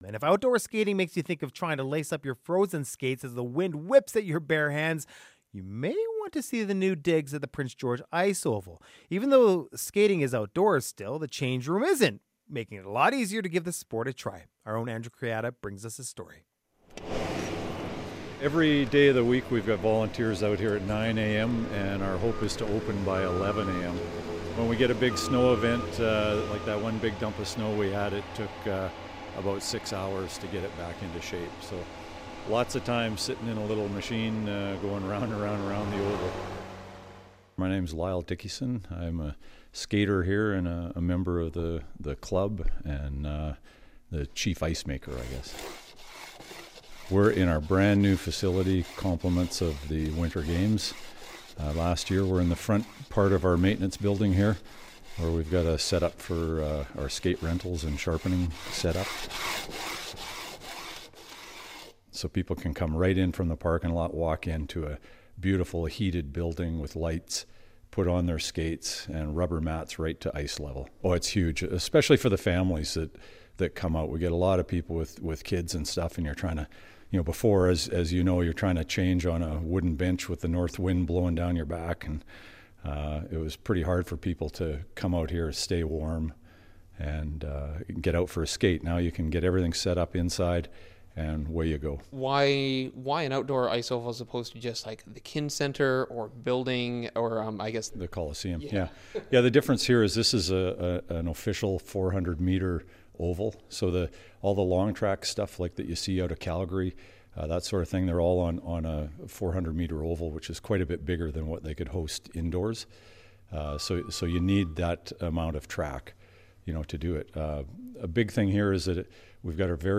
The outdoor ice oval in Prince George has some new additions, including a heated change room. We take a spin around the track to find out what it takes to keep things smooth.